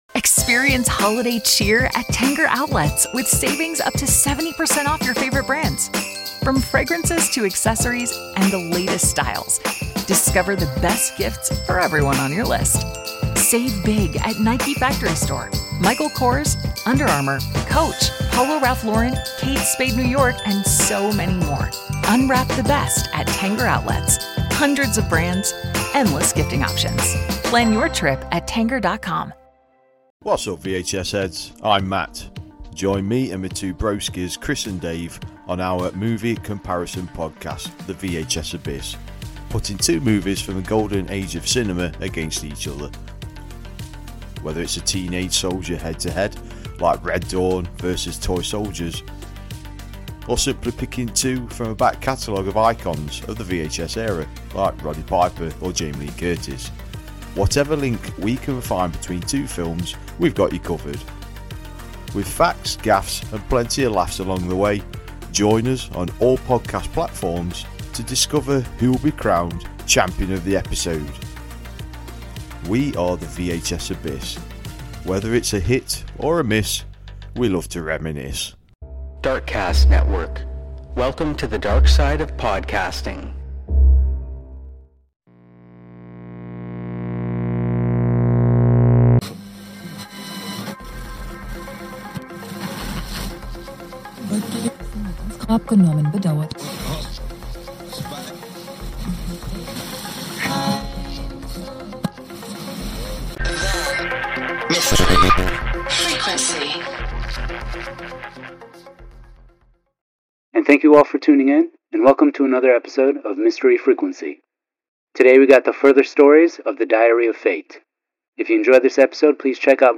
1 .426 Old Time Radio Show | Diary Of Fate [Vol 2] Classic Thriller Audio Drama 59:45